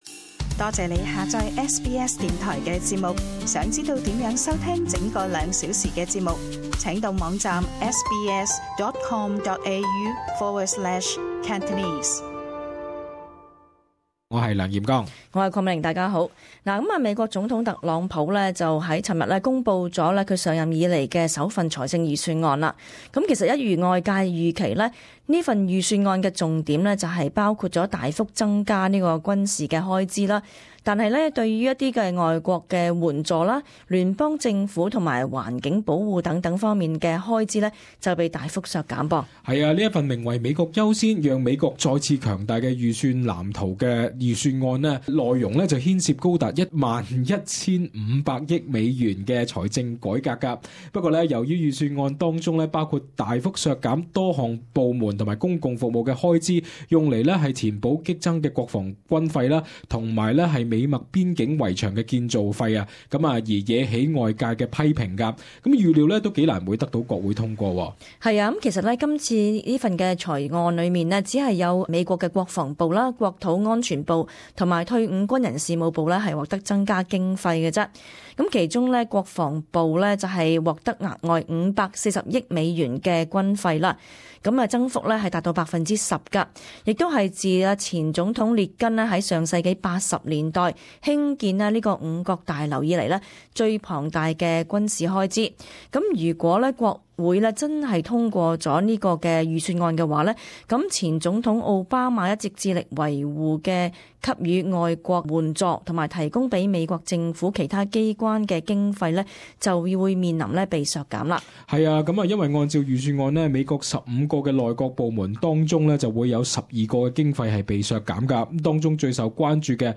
【時事報導】特朗普首份財案軍費大增惹批評